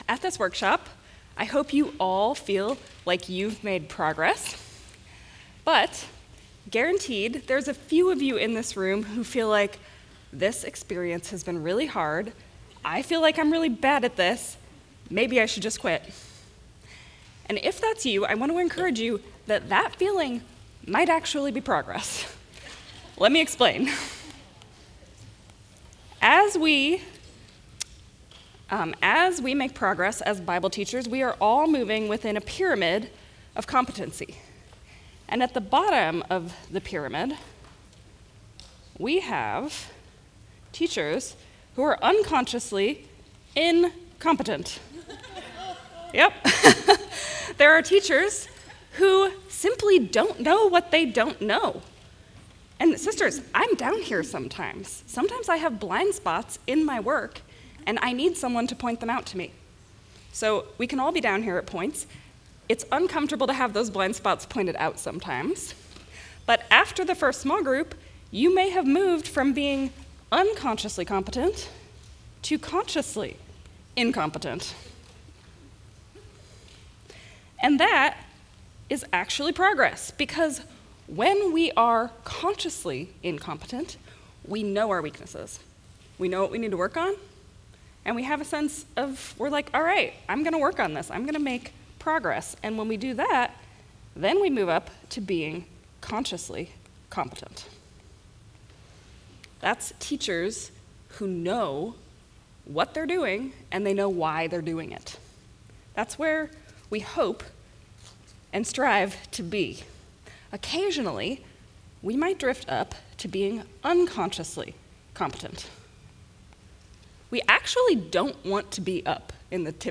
Cedar Rapids 2025